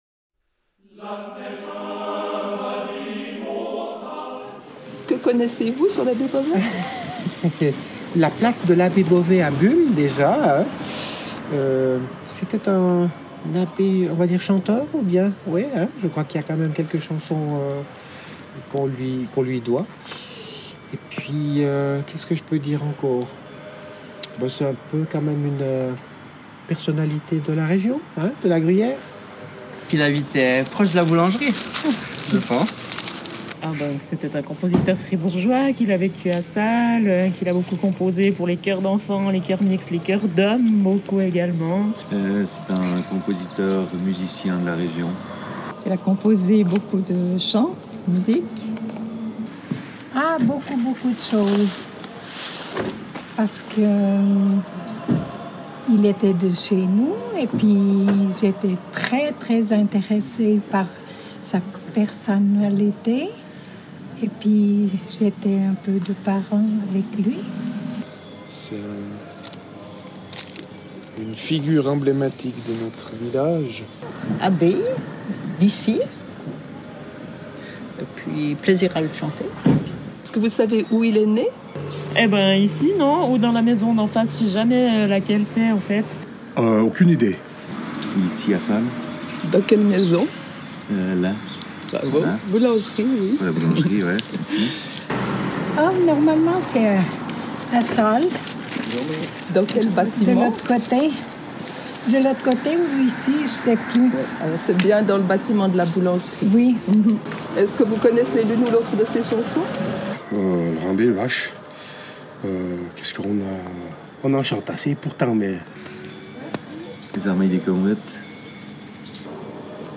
Micro-trottoir